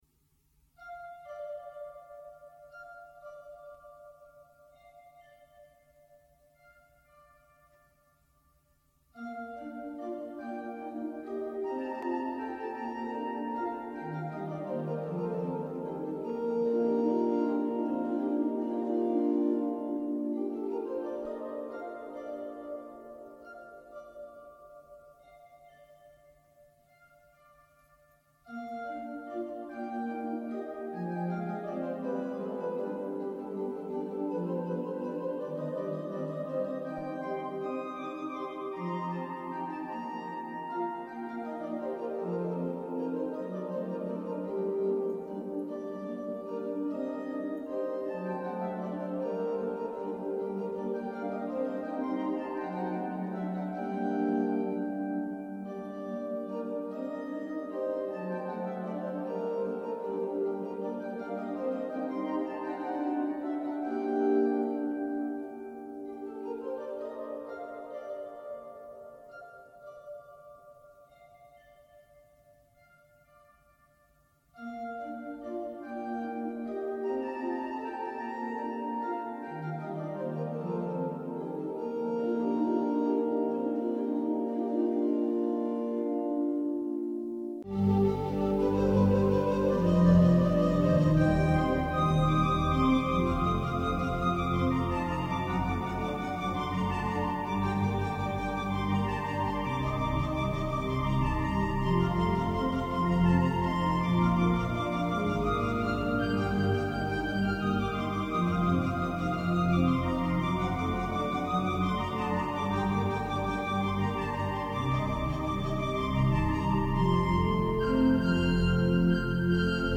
Die große Domorgel von Valladolid ist eine digitale Allen Orgel mit 19.000 Pfeifen.
wie Cembalo, Klavier, Harfe oder Panflöte,
immer begleitet von den Orgelpfeifen.
Einmalige Musik in der herrlichen Akustik des Doms zu Valladolid
mit einem Nachhall von 8 Sekunden !